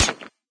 metalstone.ogg